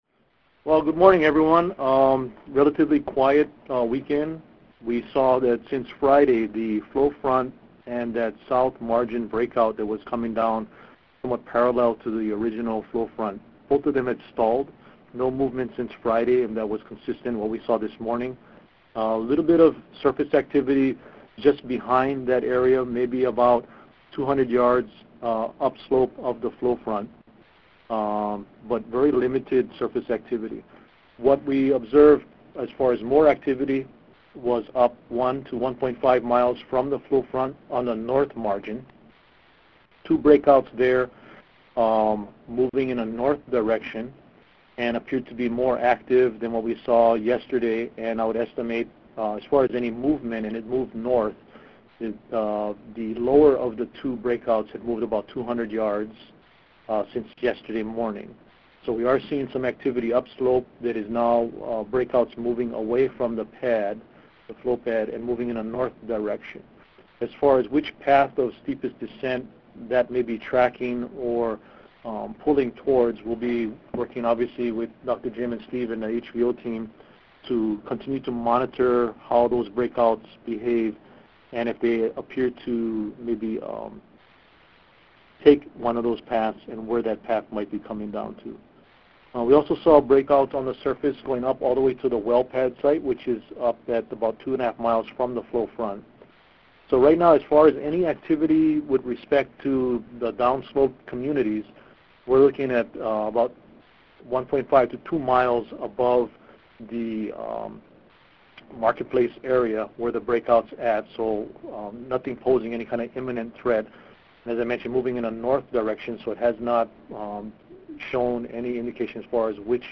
Today’s media conference call with Hawaii County Civil Defense and USGS (11:30 p.m. HST):